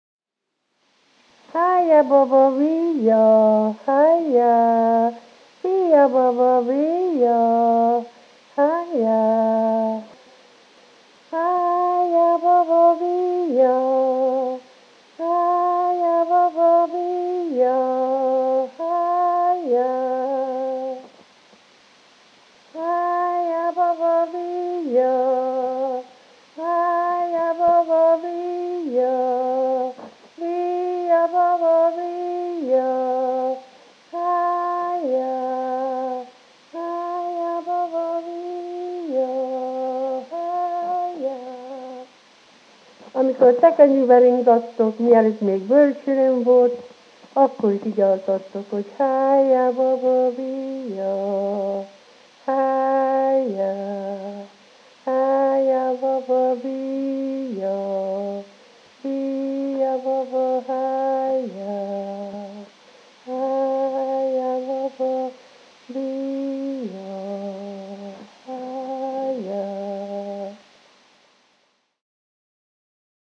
Ko so zibali dojenčka v lesenem koritu ali zibelki, so mu peli to uspavanko. Pesem je sestavljena iz različice treh besed (Heje baba, bijja).
Szalafő